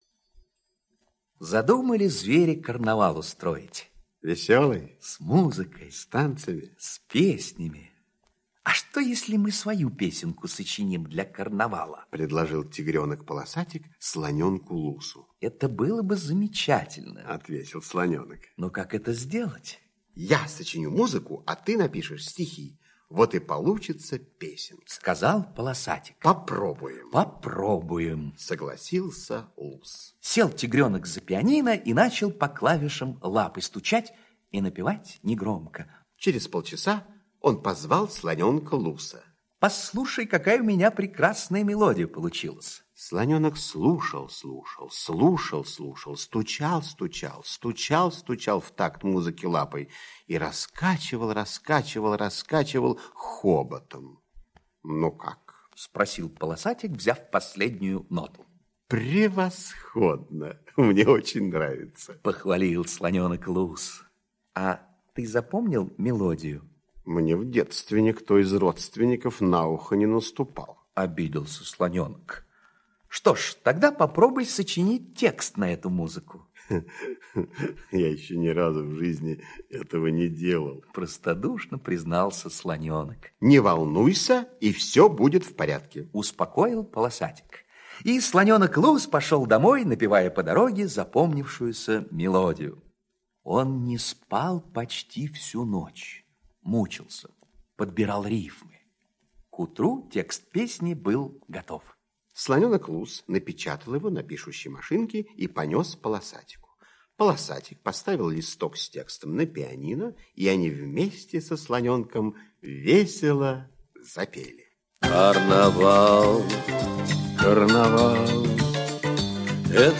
Слушайте Песенка для карнавала - аудиосказка Пляцковского М.С. Сказка про то, как тигренок и слоненок решили написать песенку для карнавала.